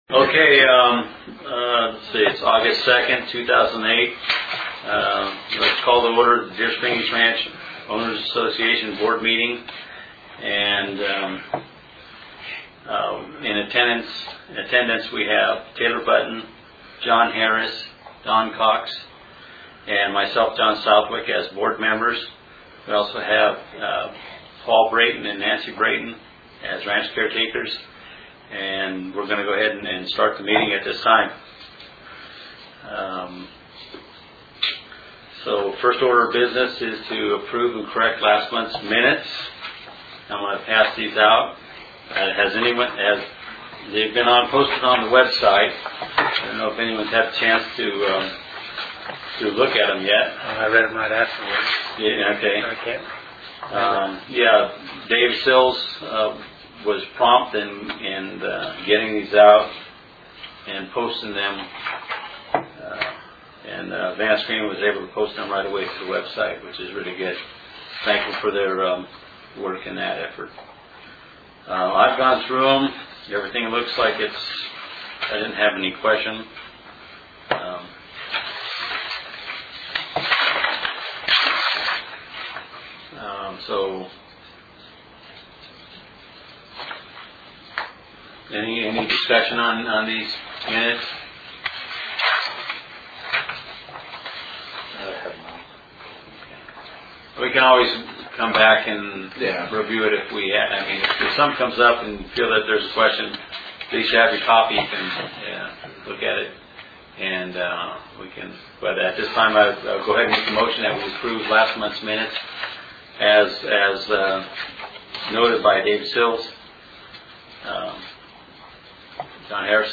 Deer Springs Ranch Board of Directors Saturday, August 02, 2008 DSR Ranch HQ 11:00AM